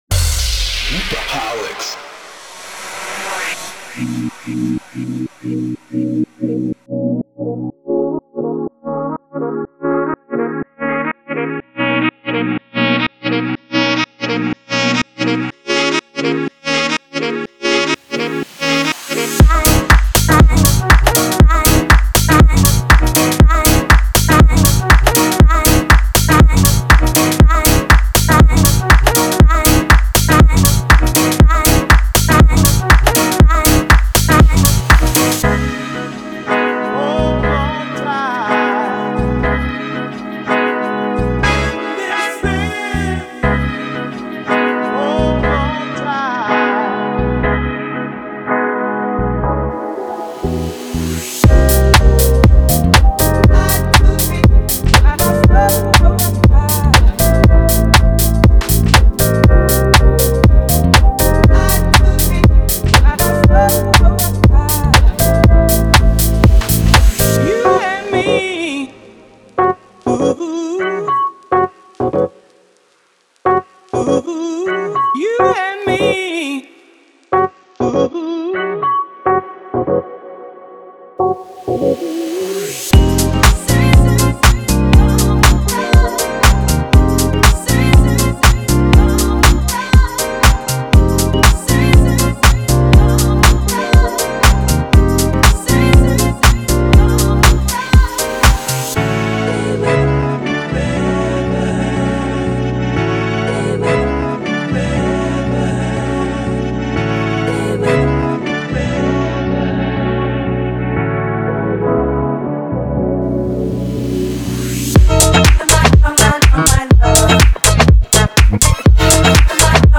Whether you’re looking to create deep house, soulful house or classic funky vibes, this pack delivers the timeless sound with a modern twist.
In detail, expect to find deep and soulful chord progressions, melodic leads, guitars, soft pads, e-pianos and many more.
Covering diverse ground between tempos, this pack works harmoniously with the named genre but also suits Deep House, Classic House, Lo-Fi and more.
• 128 Pianos, Pads Synth & Chord Loops
• 12 Drums Loops
• 32 Bass Loops
• Tempo Range 120-126 BPM